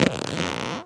foley_leather_stretch_couch_chair_21.wav